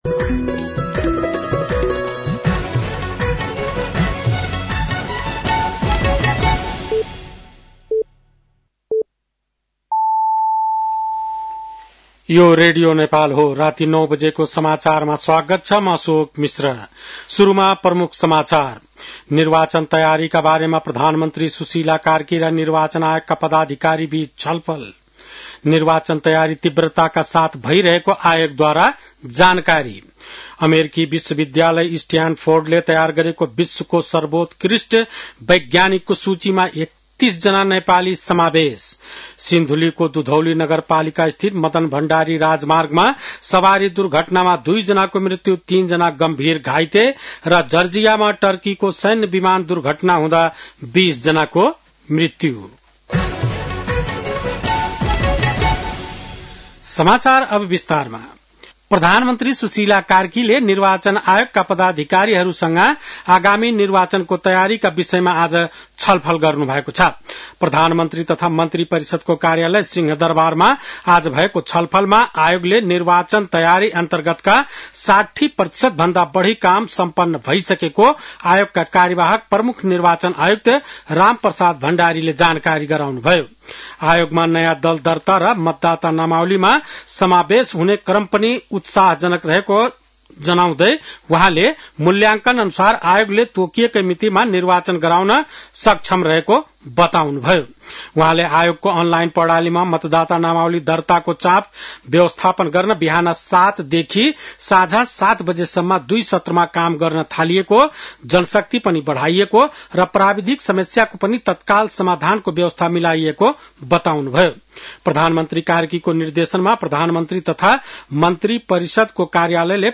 बेलुकी ९ बजेको नेपाली समाचार : २६ कार्तिक , २०८२
9-pm-nepali-news-7-26.mp3